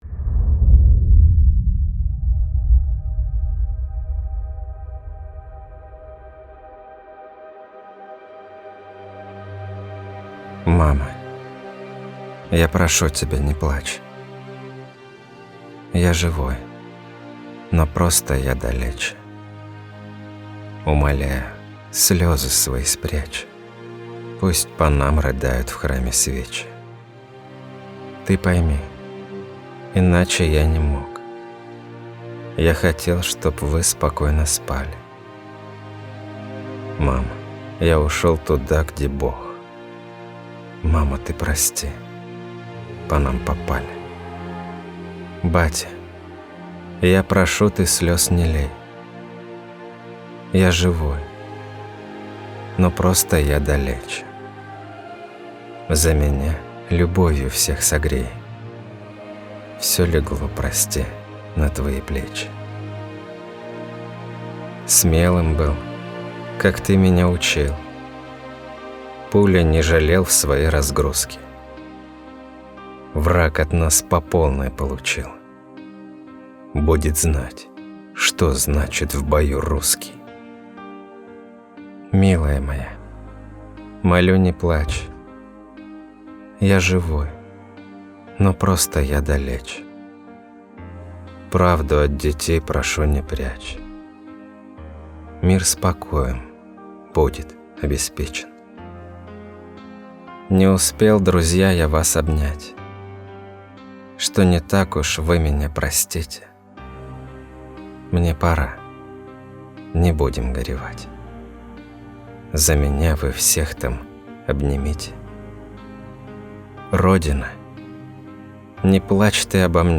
- Я_ЖИВОЙ - СТИХ
_-Ya_ZHIVOQ-_-STIH.mp3